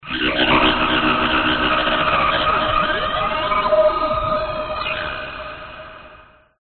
拥挤的地方
描述：有很多人的地方
标签： 抖动 说话 喋喋不休 声音 女孩 讲话 聊天 地方 女人 马斯特里赫特 聊天 争论 聊天 人群中 认为
声道立体声